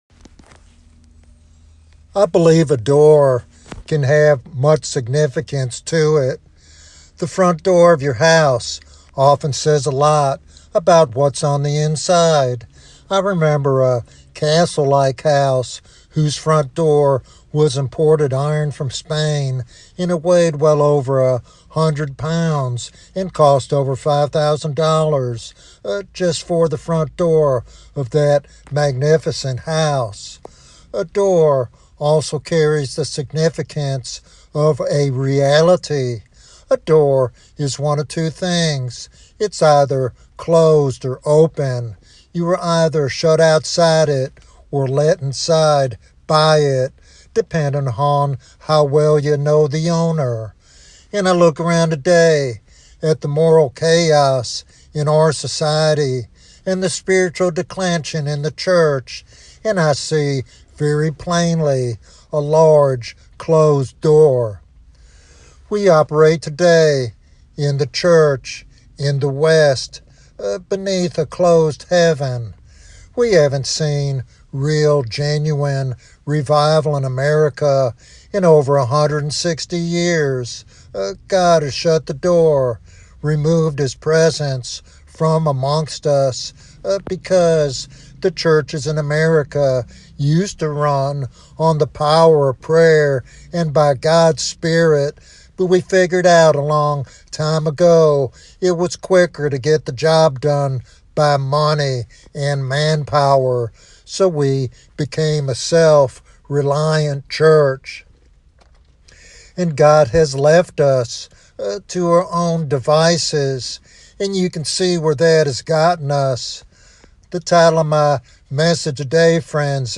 This sermon challenges listeners to confront pride and self-reliance and embrace the transformative power of God's presence.